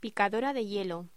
Locución: Picadora de hielo
voz